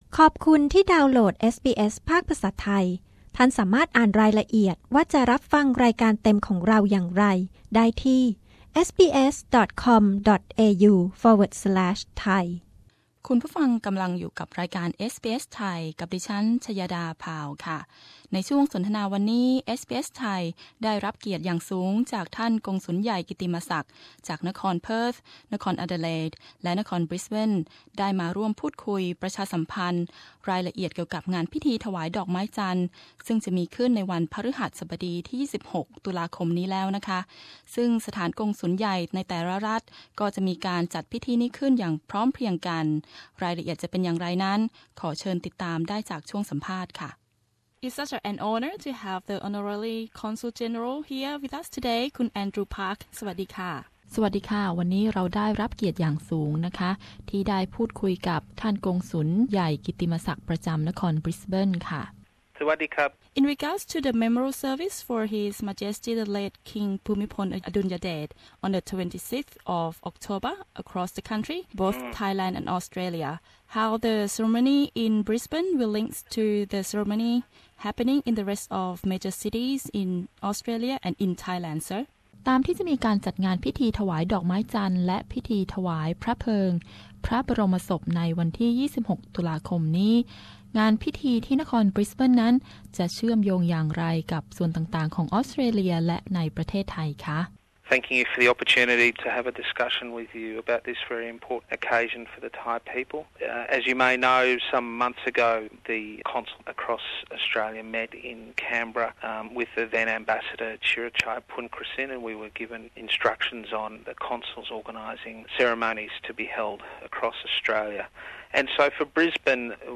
สาส์นจากท่านกงสุลใหญ่กิตติมศักดิ์ ประจำเมืองอดิเลท บริสเบนและเพิร์ธ เชิญชวนประชาชนเข้าร่วมงานพิธีถวายดอกไม้จันทน์ที่จะมีขึ้นในเย็นวันนี้